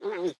Minecraft Version Minecraft Version 25w18a Latest Release | Latest Snapshot 25w18a / assets / minecraft / sounds / mob / armadillo / ambient1.ogg Compare With Compare With Latest Release | Latest Snapshot